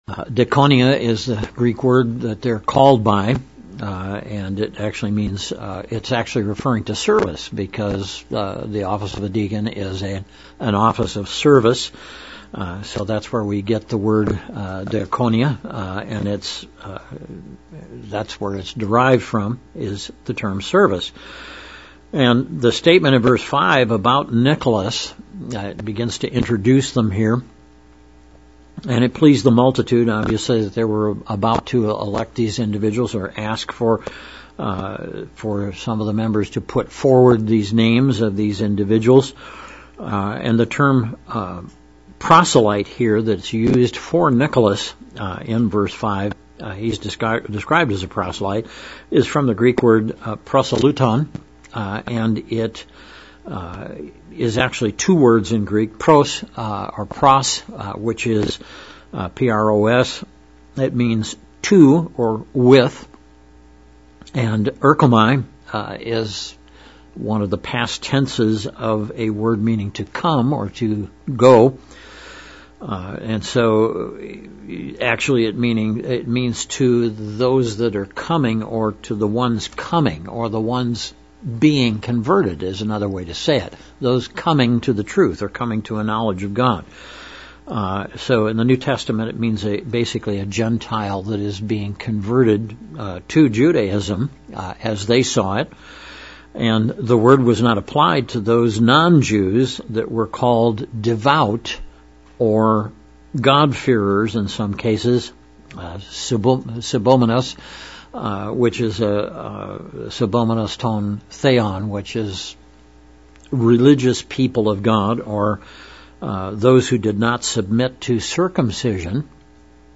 Bible Study: Acts of the Apostles - Chapter 6:4 - 7:34
Given in Central Illinois